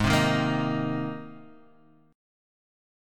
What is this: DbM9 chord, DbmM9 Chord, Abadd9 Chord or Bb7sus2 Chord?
Abadd9 Chord